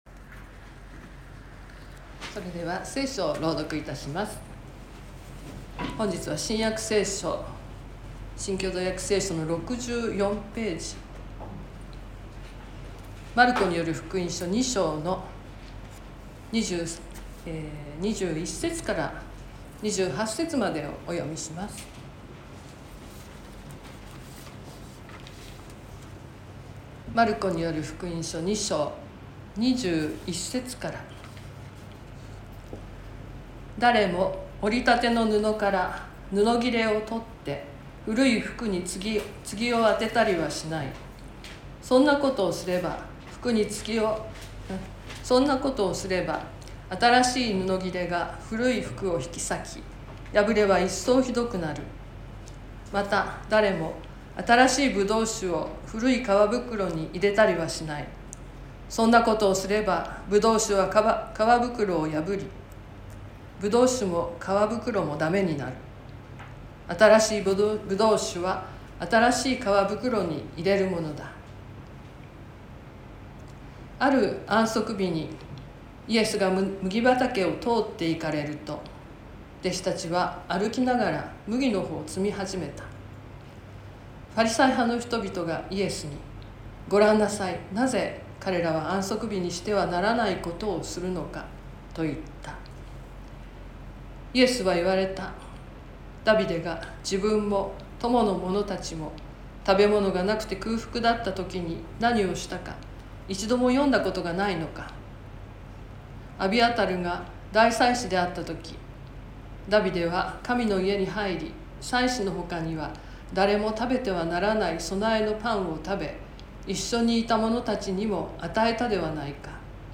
Youtubeで直接視聴する 音声ファイル 礼拝説教を録音した音声ファイルを公開しています。